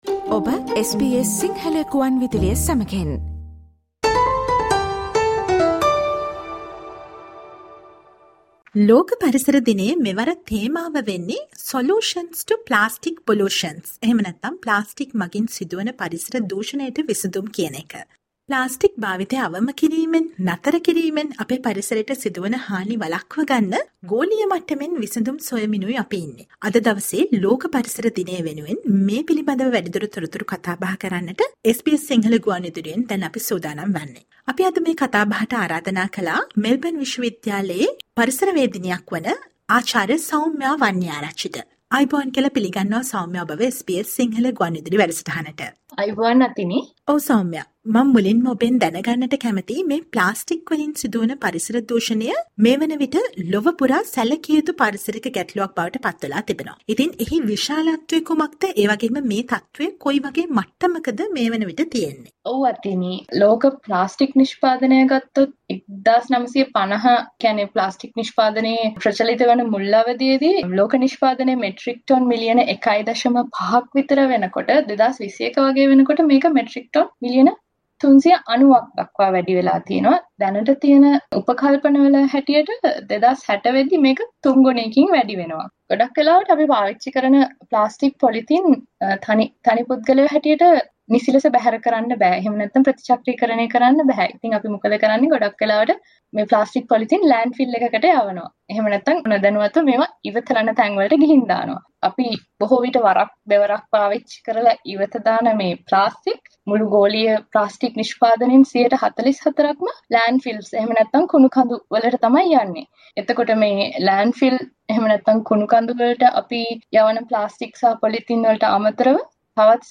Listen to the SBS Sinhala interview